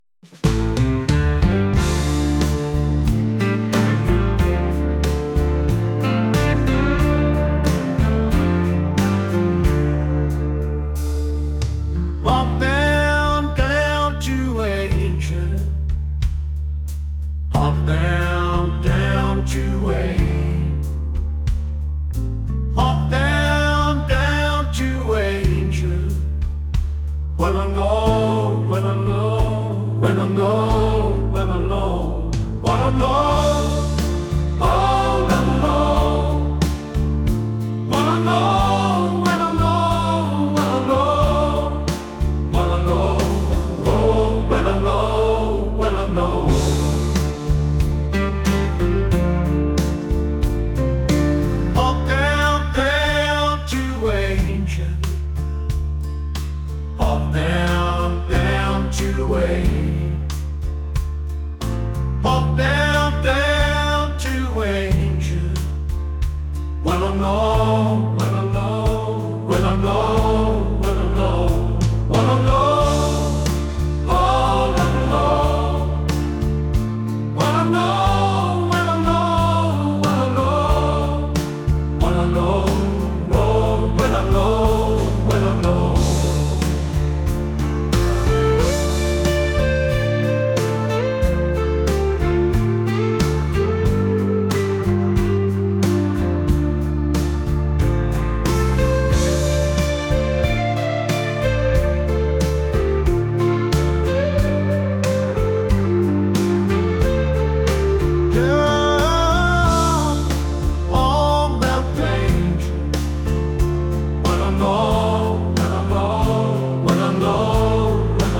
country | soul & rnb | acoustic